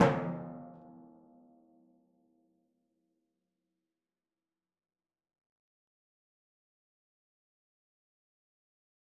Timpani4_Hit_v4_rr1_Sum.wav